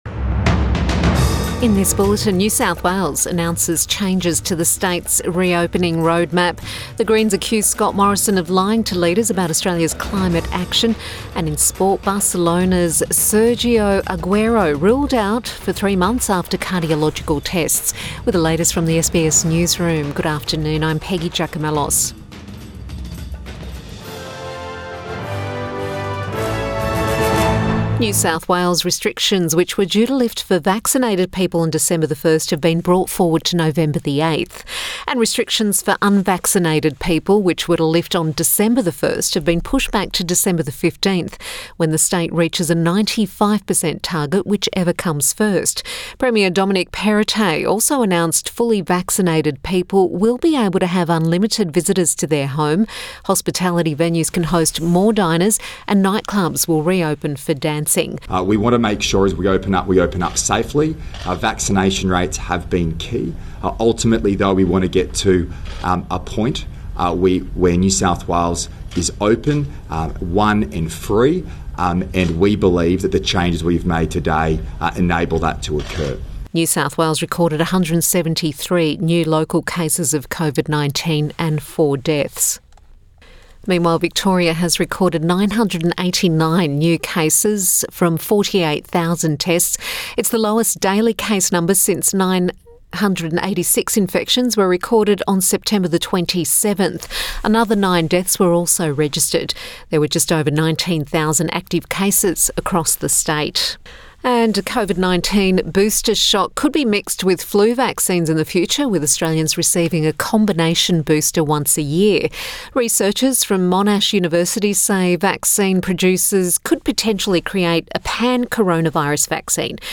Midday bulletin 2 November 2021